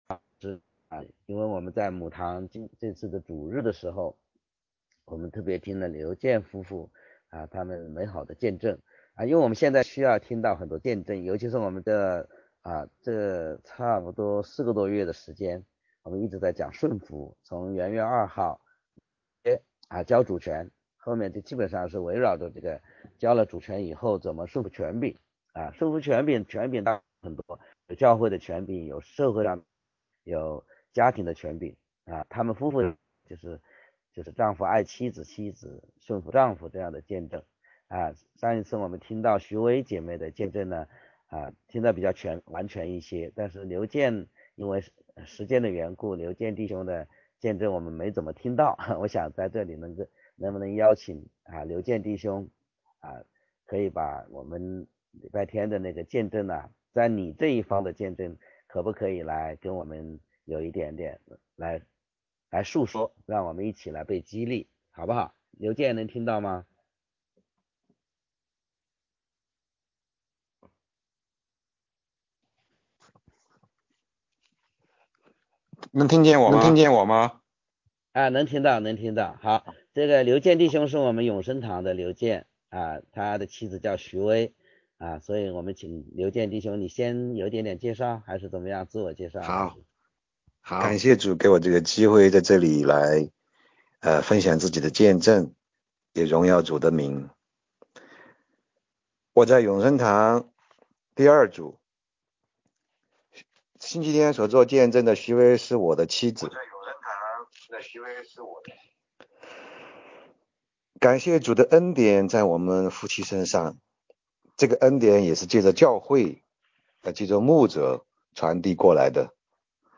见证分享